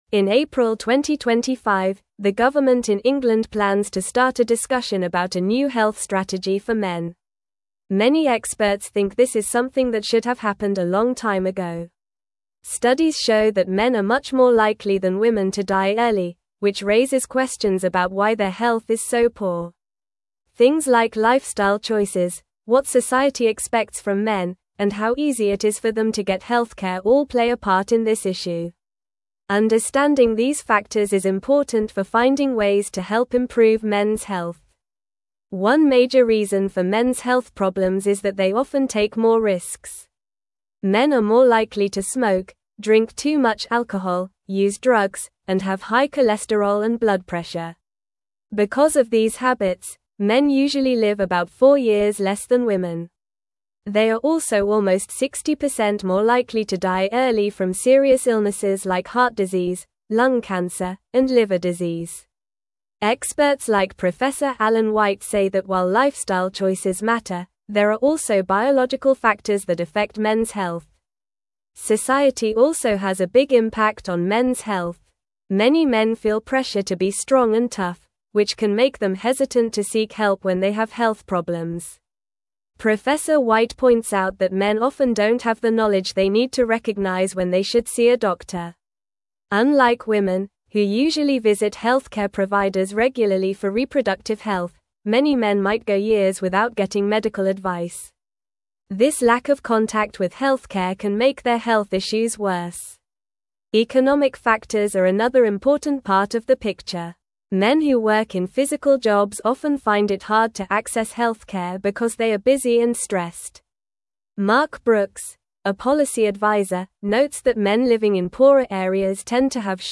Normal
English-Newsroom-Upper-Intermediate-NORMAL-Reading-UK-Government-Launches-Consultation-for-Mens-Health-Strategy.mp3